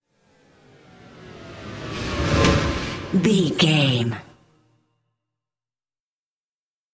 Thriller
Aeolian/Minor
strings
drums
cello
violin
percussion
tension
ominous
dark
suspense
haunting
creepy